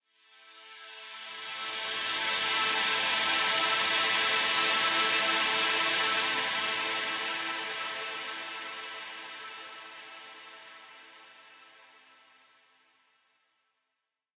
SaS_HiFilterPad03-A.wav